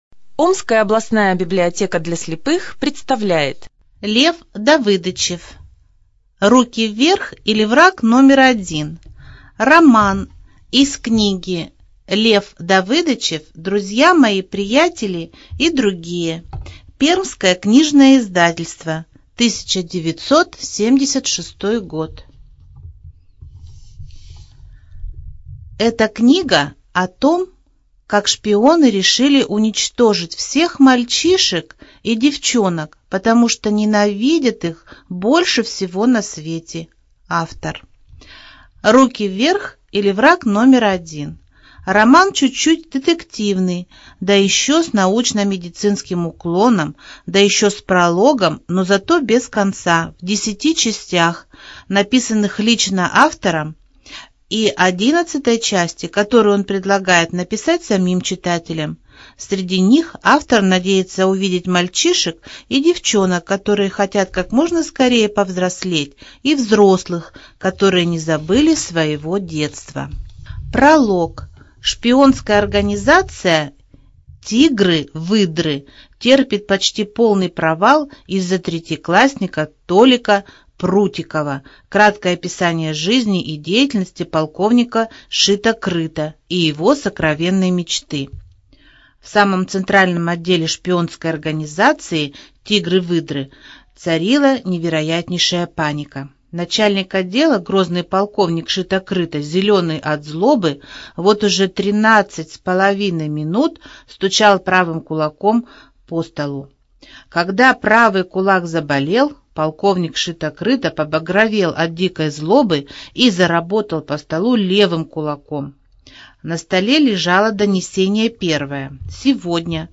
ЖанрДетская литература
Студия звукозаписиОмская областная библиотека для слепых